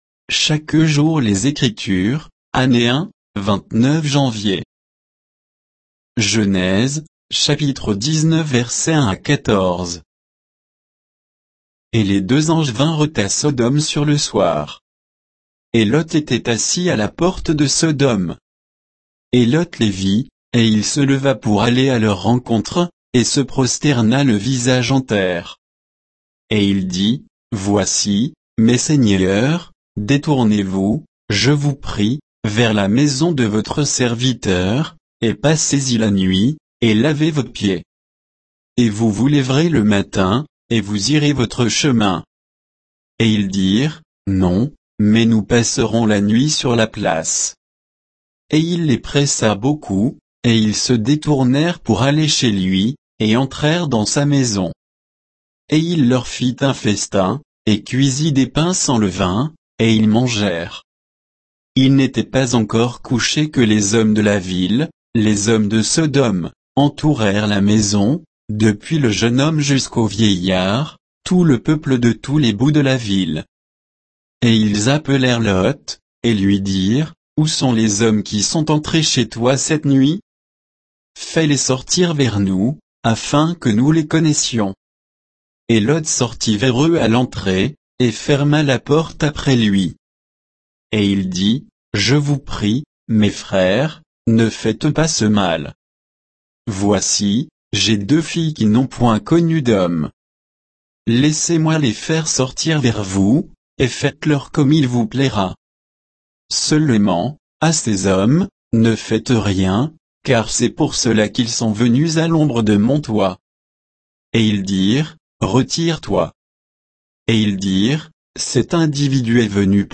Méditation quoditienne de Chaque jour les Écritures sur Genèse 19